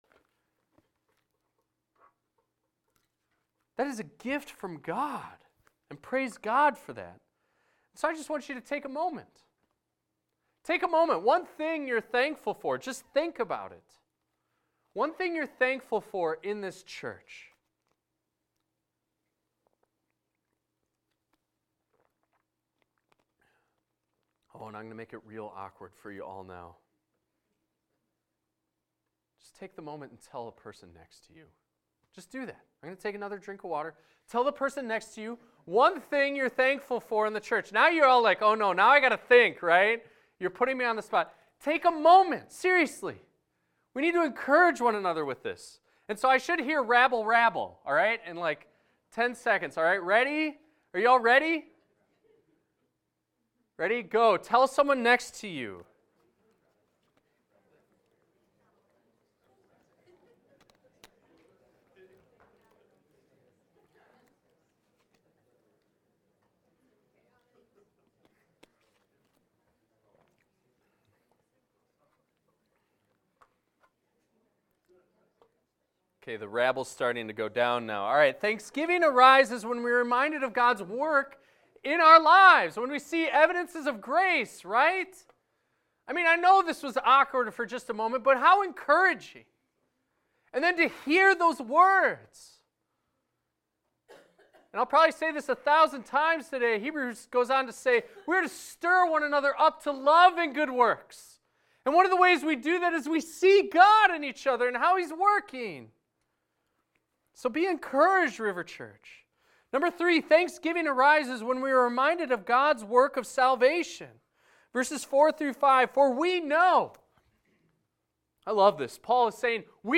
This is a recording of a sermon titled, "Thanking God for Faith."